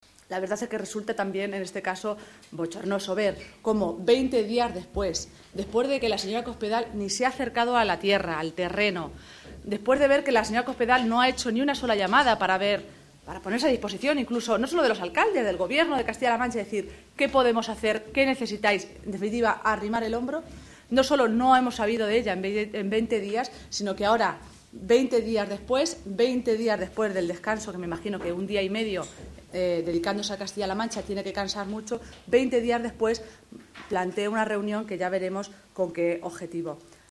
La senadora socialista Cristina Maestre critica que la secretaria general del PP lleve ausente de nuestra Región veinte días, “lo que demuestra, una vez más que no le interesa Castilla-La Mancha”
Cortes de audio de la rueda de prensa